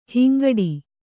♪ hingaṭṭu